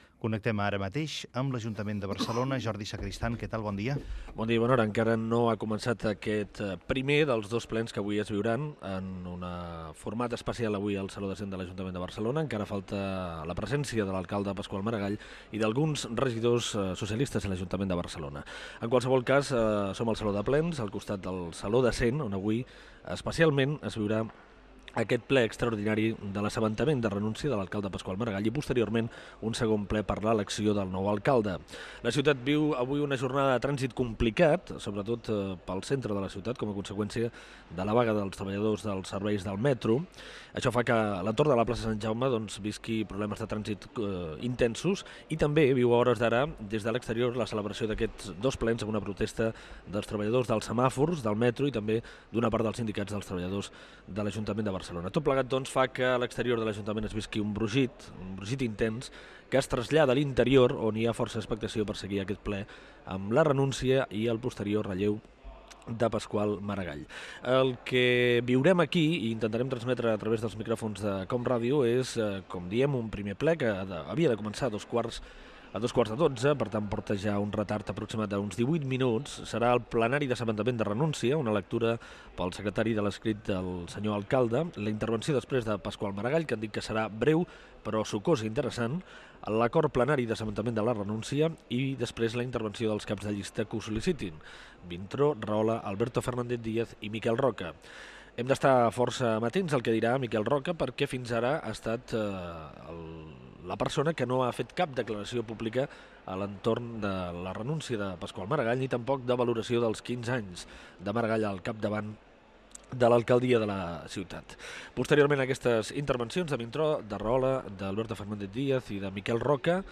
Transmissió del ple de l'Ajuntament de Barcelona en el qual Pasqual Maragall renunciava a ser alcalde de la ciutat i Joan Clos seria escollit com a nou alcalde.
Informatiu
Fragment extret de l'arxiu sonor de COM Ràdio.